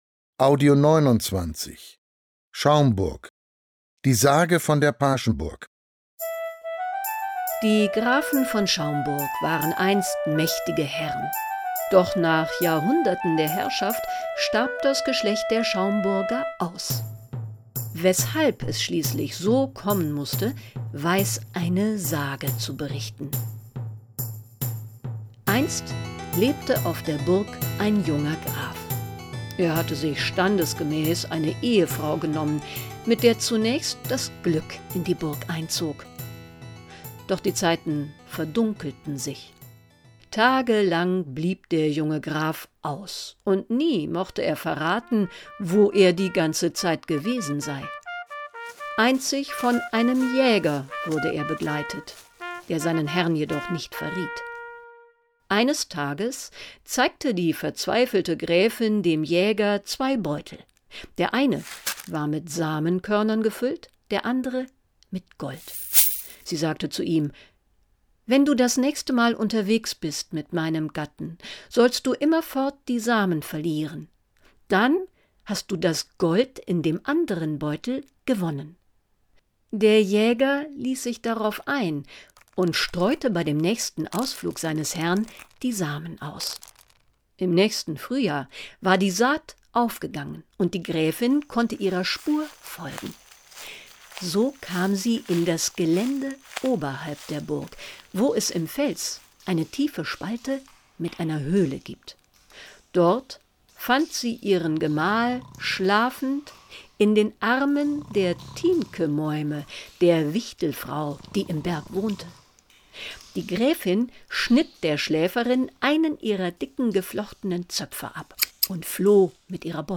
Audioguide